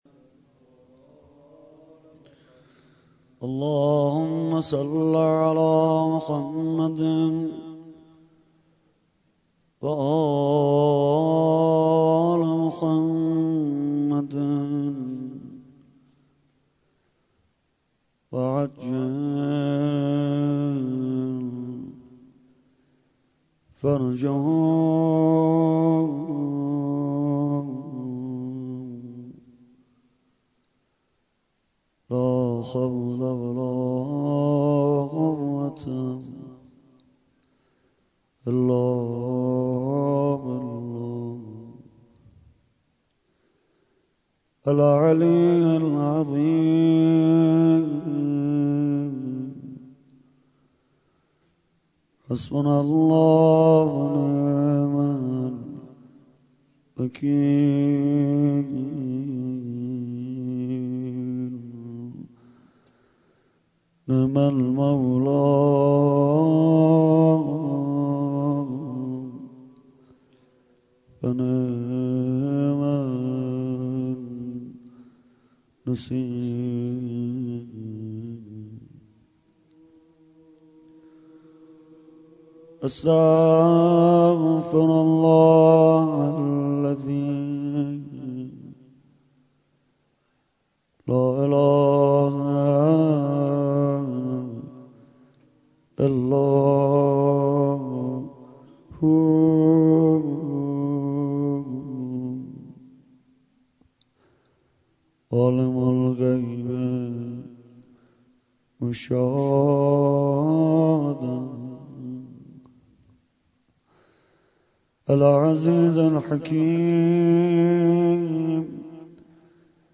مناجات و قسمت اول دعا خوانی